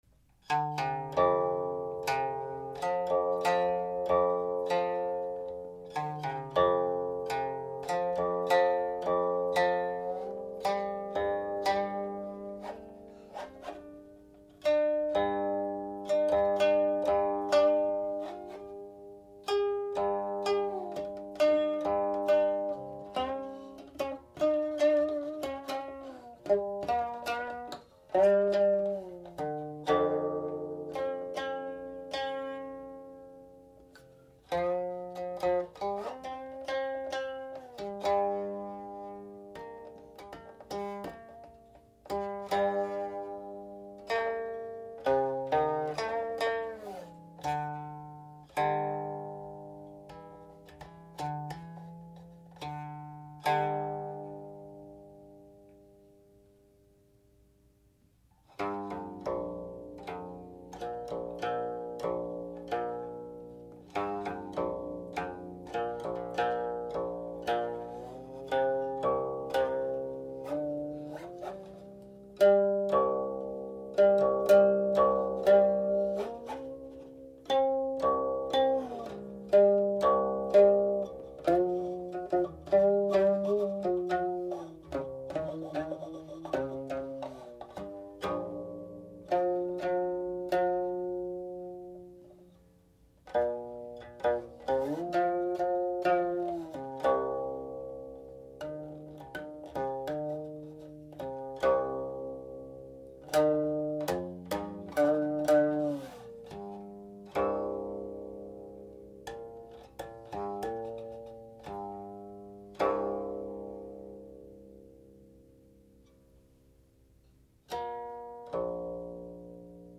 Section One uses only the fourth to seventh strings, while Section Two has nearly identical tablature except that it is set to the first to fourth strings. This makes all the relative pitches of the second section a fifth lower than those of the first section. The closing harmonic coda then stands out both for being short and for its apparent change in tonality - it is three notes, all 1 (do).
The lyrics were paired in a largely syllabic setting.